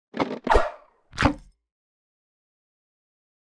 longbow-003-90ft.ogg